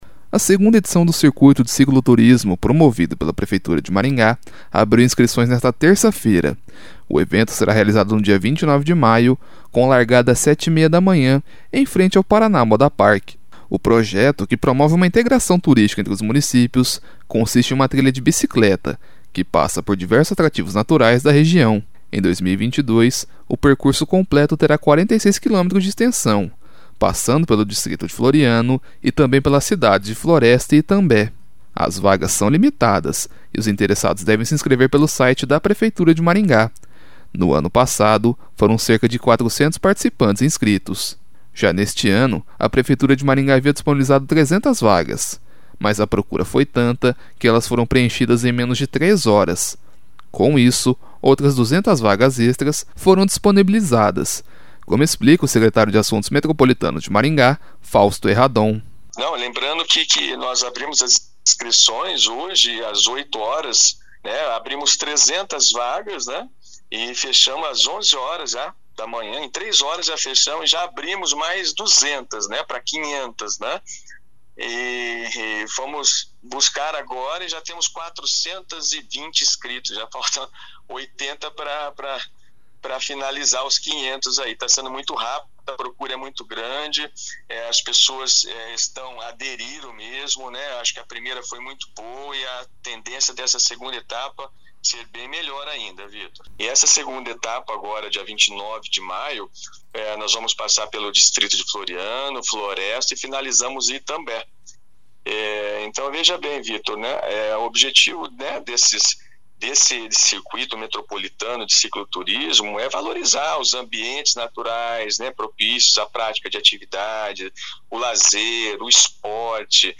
Com isso, outras 200 vagas extras foram disponibilizadas, como explica o Secretário de Assuntos Metropolitanos de Maringá, Fausto Herradon.